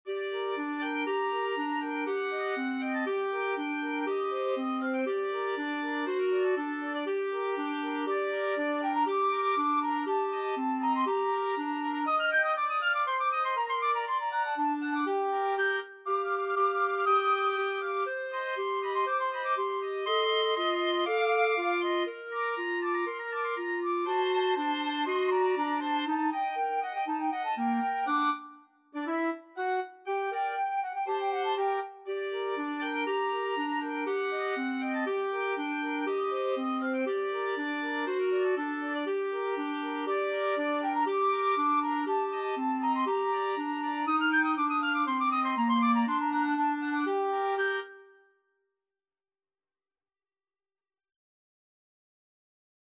Free Sheet music for Flute-Oboe-Clarinet
FluteOboeClarinet
G minor (Sounding Pitch) (View more G minor Music for Flute-Oboe-Clarinet )
Allegro (View more music marked Allegro)
2/4 (View more 2/4 Music)
Classical (View more Classical Flute-Oboe-Clarinet Music)
brahms_hungarian_dance_5_FLOBCL.mp3